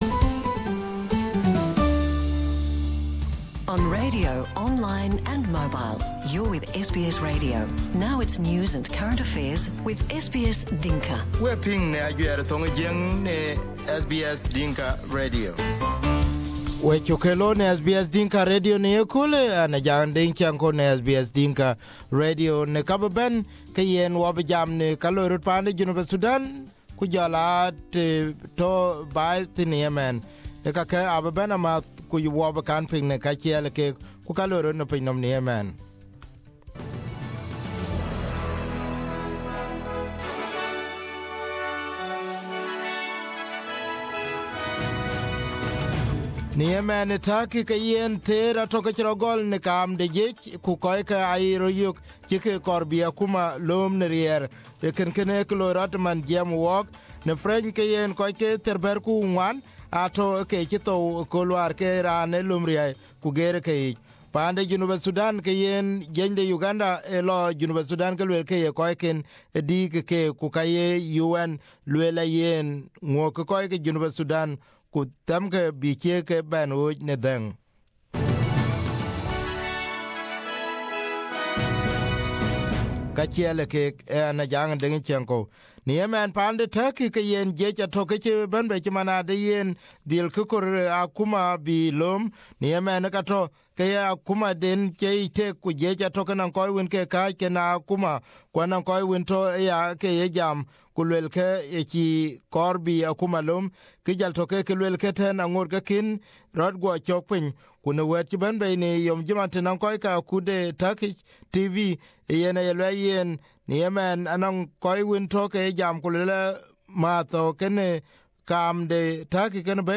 SBS Dinka Radio News